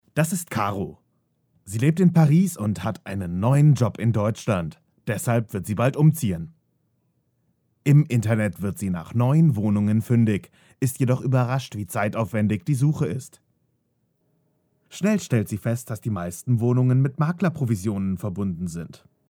Die Deutsche Stimme mit Variation und viel Charakter
Kein Dialekt
Sprechprobe: Werbung (Muttersprache):